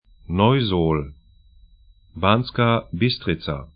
Neusohl 'nɔyzo:l Banská Bystrica
'banska: